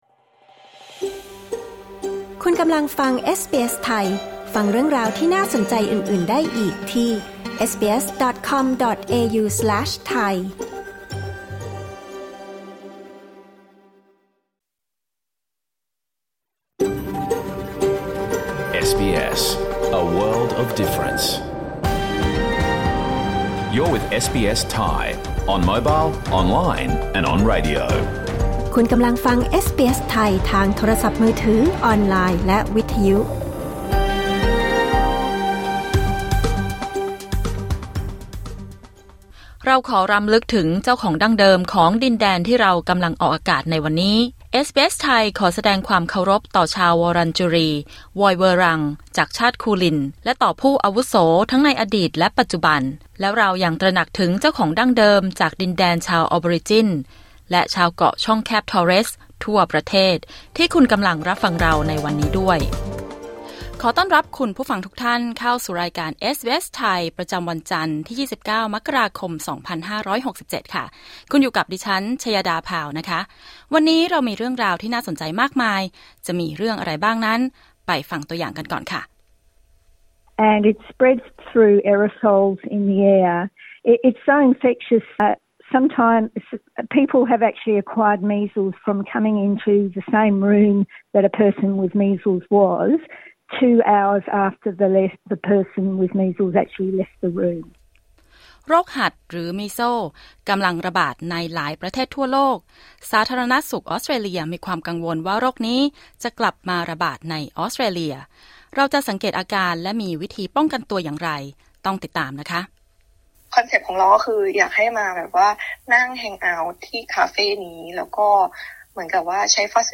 รายการสด 29 มกราคม 2567